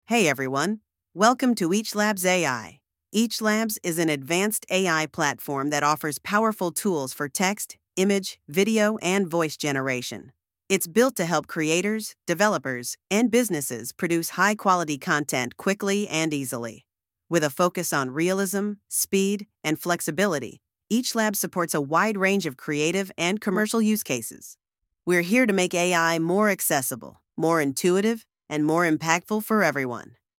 Sesleri doğal netlik ve duyguyla dönüştürün - anında klonlama, çok dilli çıktı ve üretim için hazır ses için hassas stil kontrolü.
elevenlabs-voice-changer-output.mp3